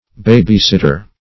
babysitter.mp3